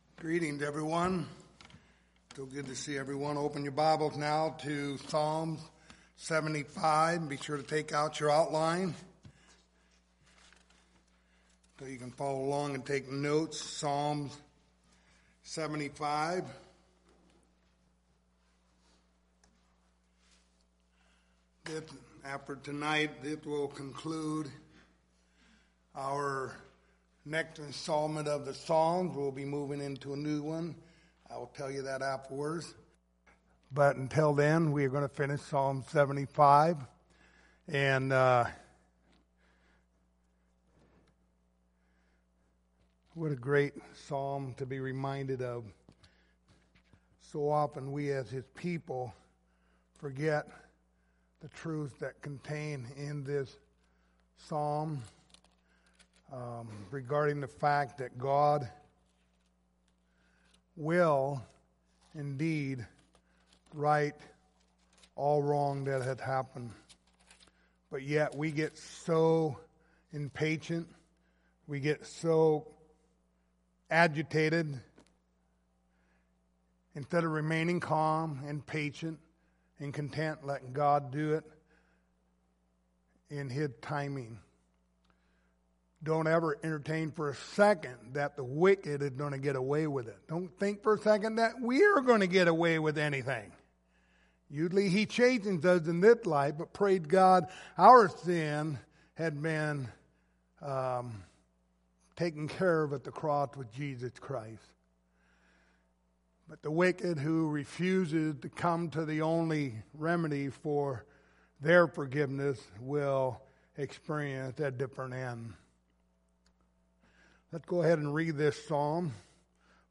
Psalms 75:1-10 Service Type: Sunday Evening Topics